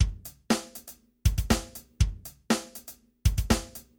破鼓120
描述：嗯，有几个汤姆的循环，自然的鼓声。
标签： 120 bpm Rock Loops Drum Loops 689.15 KB wav Key : Unknown
声道立体声